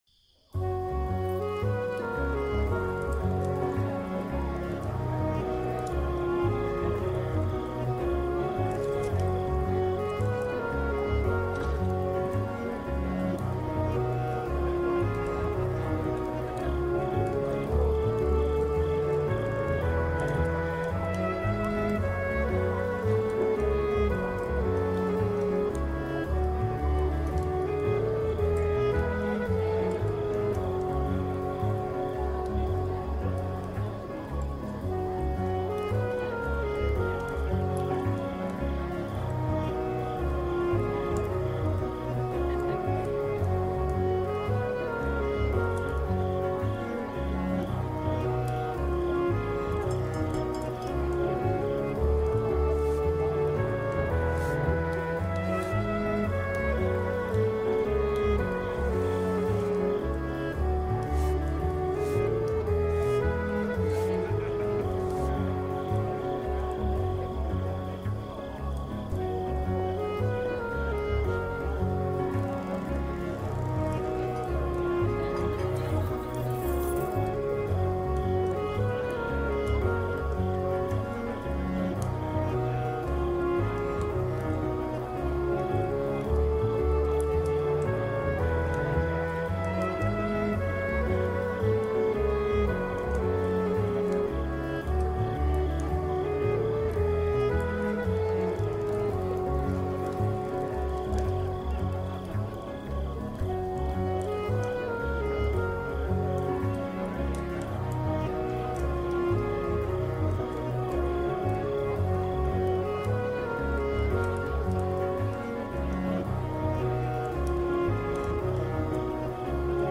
your ultimate destination for calming vibes, chill beats